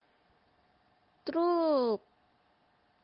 truk.mp3